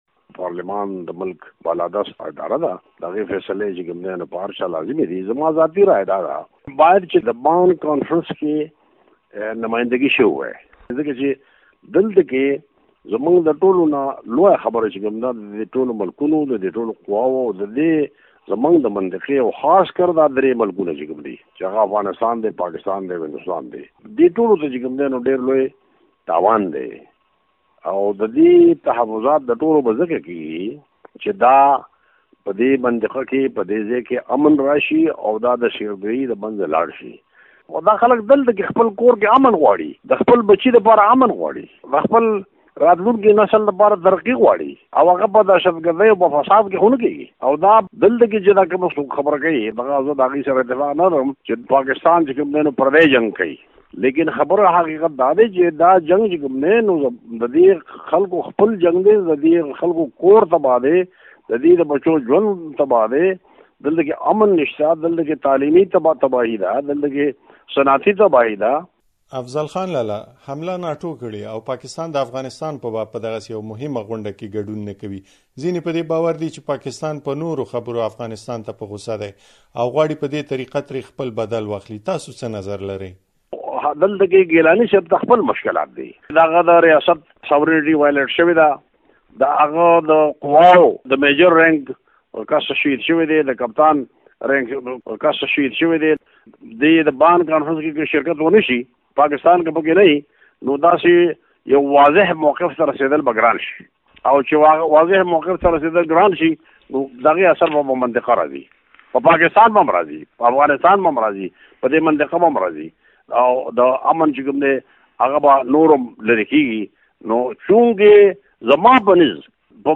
له افضل خان لالا سره مرکه